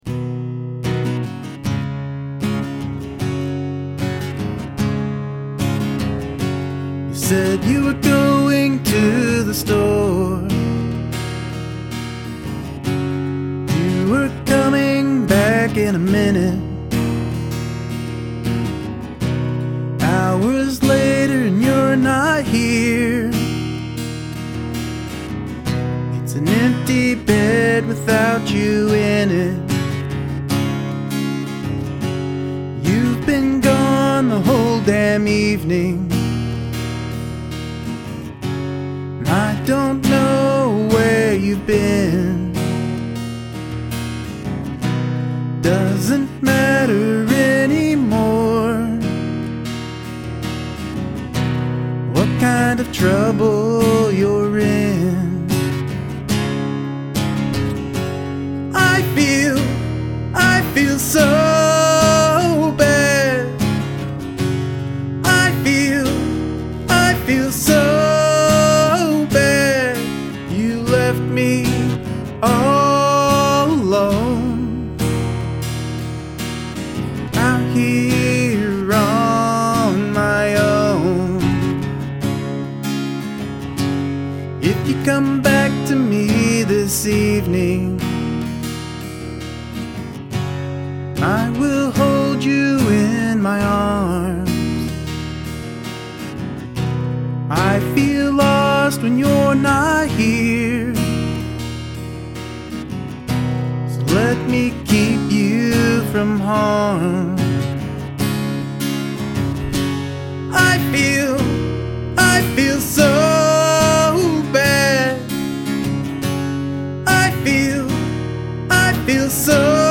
The gtr solo was very fine idea and you played it well with good sounds.
Good song, good recording. excellent vox.
is the expression in your vox during the chorus!!!!
Really liked this one the guitar sounds really great and the vox a really superb, look forward to your song next week.